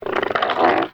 ALIEN_Communication_20_mono.wav